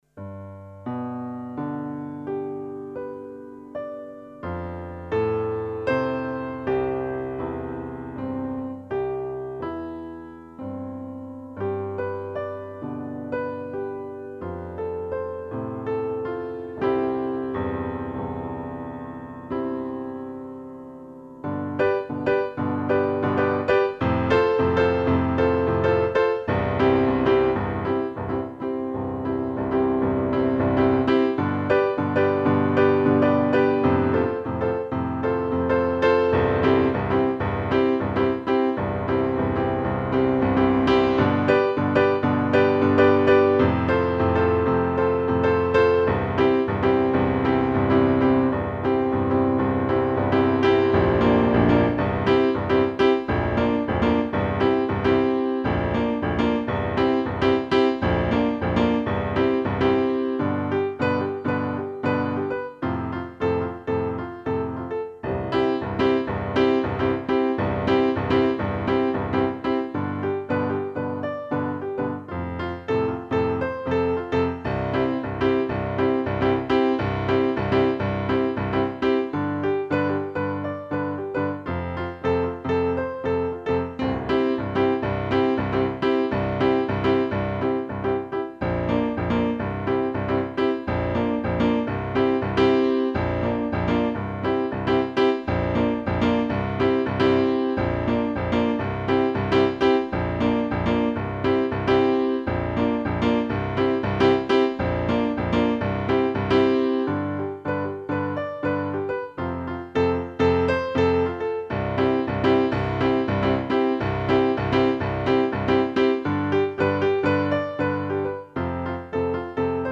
Tempo: 108 bpm / Datum: 01.11.2013
Schnellsuche Instrumental Piano